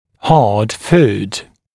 [hɑːd fuːd][ха:д фу:д]грубая, твердая еда